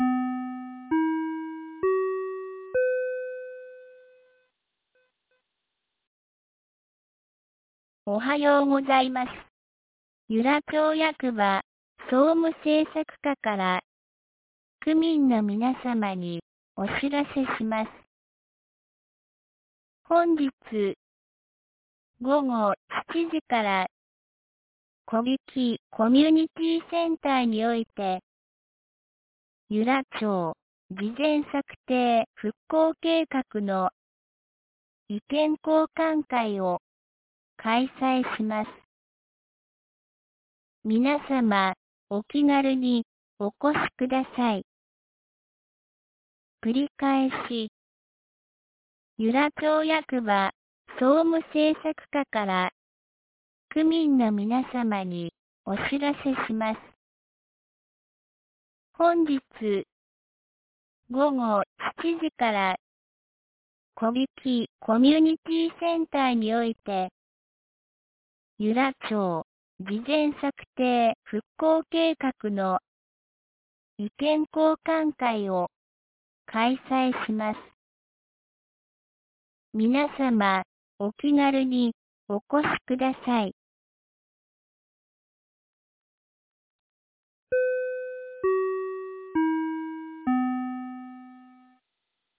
2022年11月05日 07時53分に、由良町から小引地区へ放送がありました。